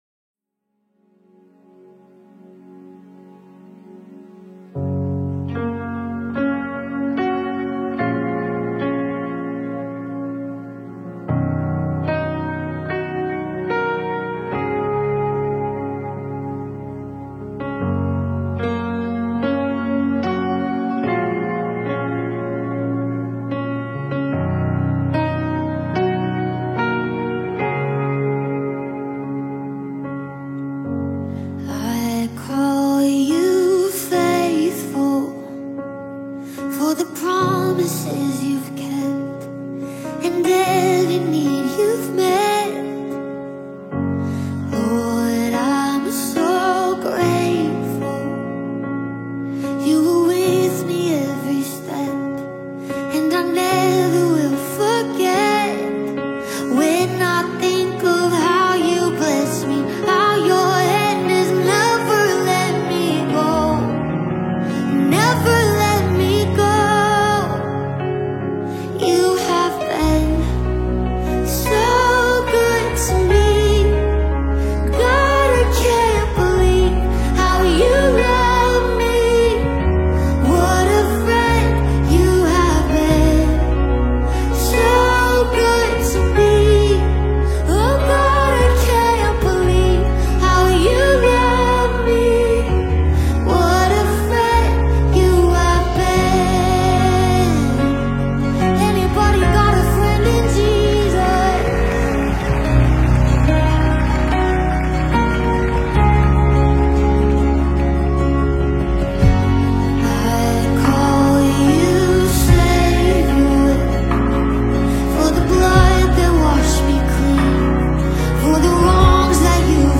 the acclaimed gospel music group